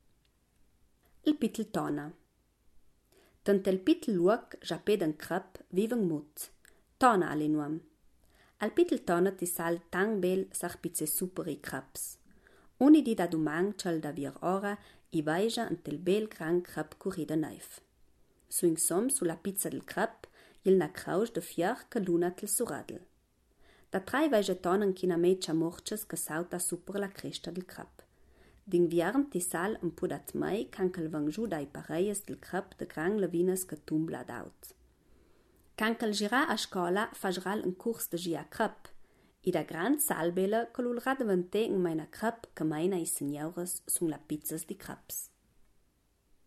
Ladino gardenese